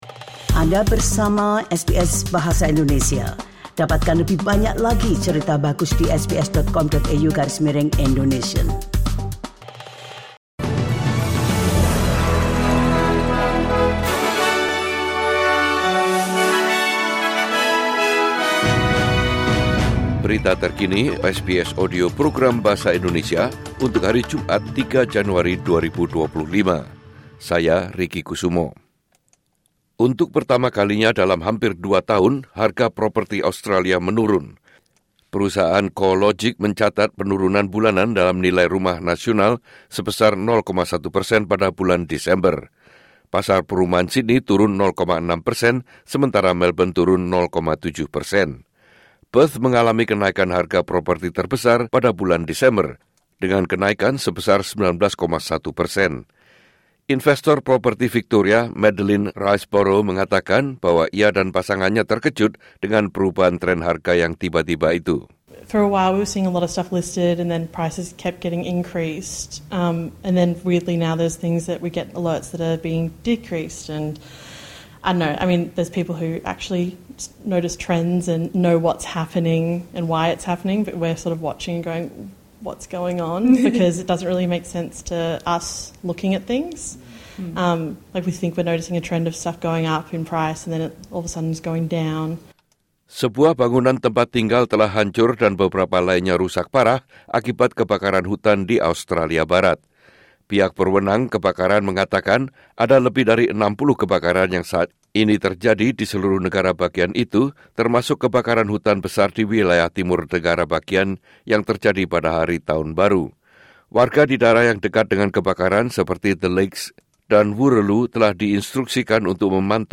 Warta Berita Radio SBS Program Bahasa Indonesia Source: SBS